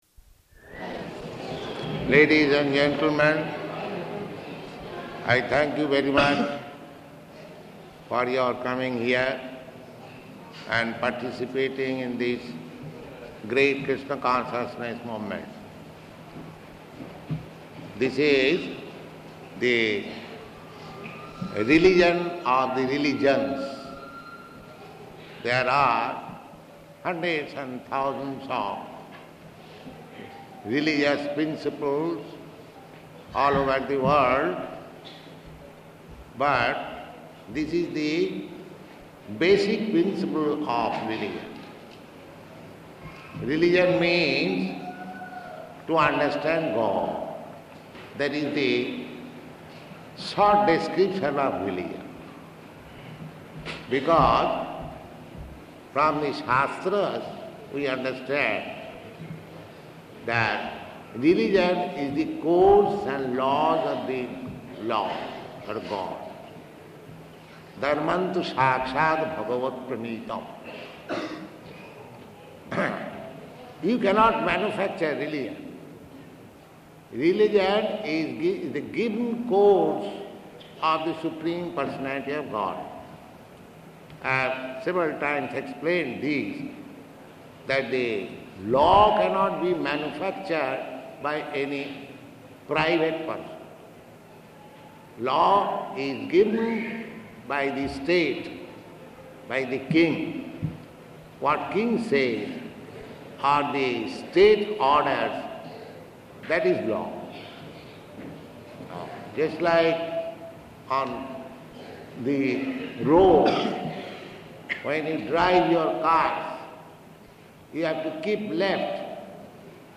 Lecture to Railway Workers
Type: Lectures and Addresses
Location: Visakhapatnam
[audio speeds up]